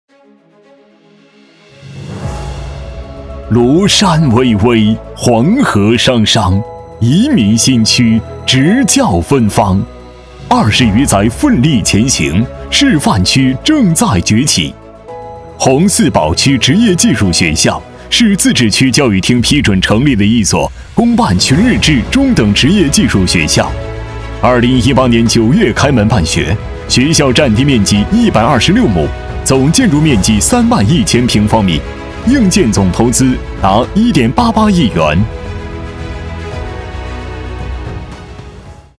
配音试听
职业技术学校配音欣赏